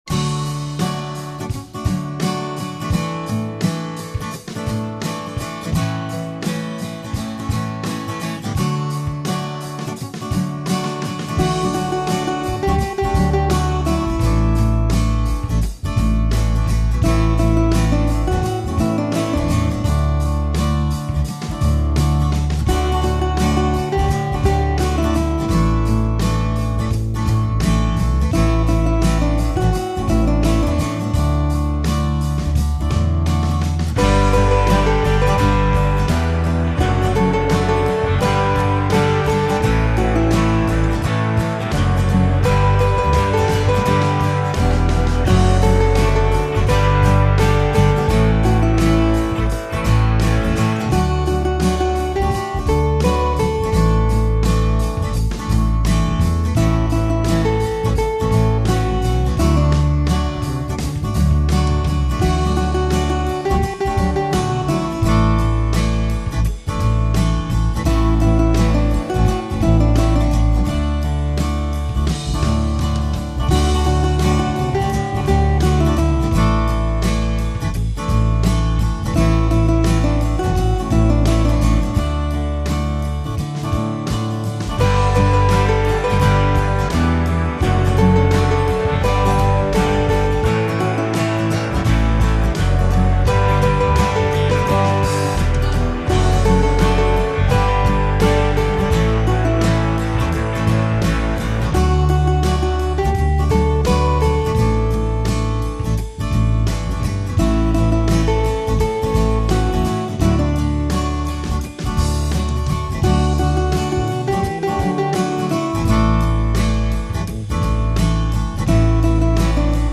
It’s a highly syncopated modern chant if you like.